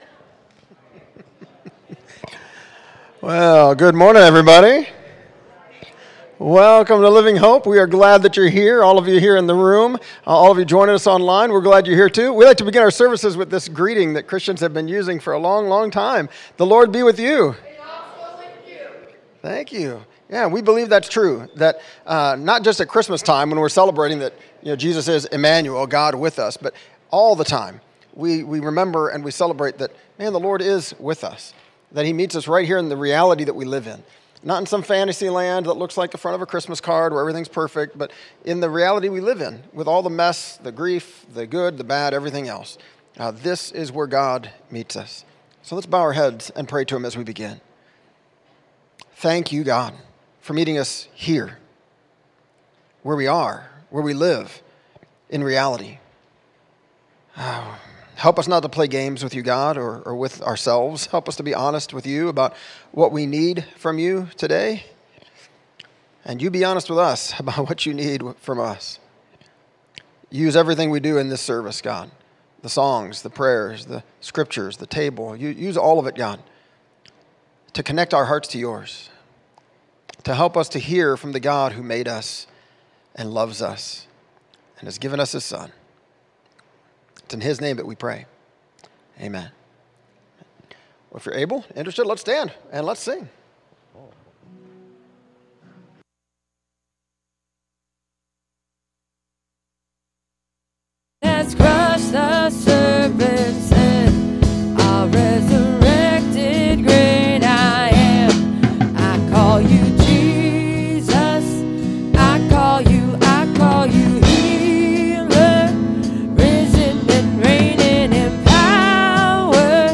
Sunday messages from Living Hope Community Church in Valparaiso, Indiana